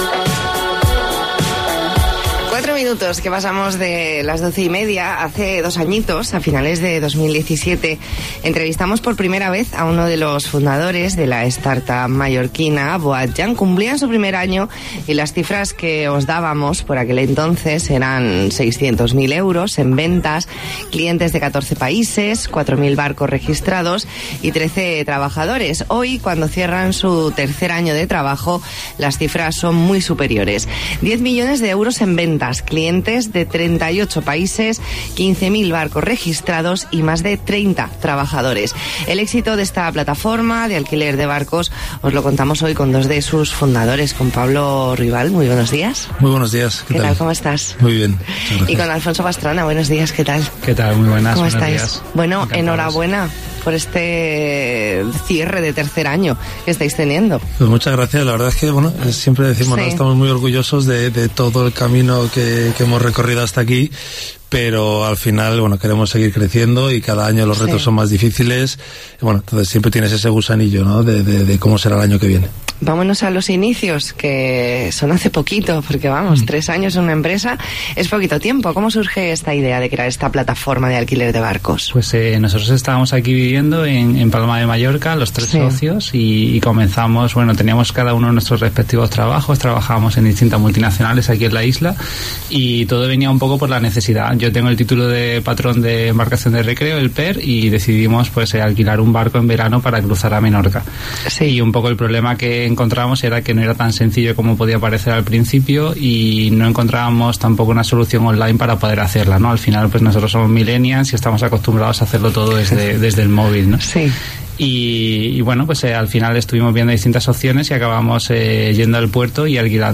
Entrevista en La Mañana en COPE Más Mallorca, jueves 12 de diciembre de 2019.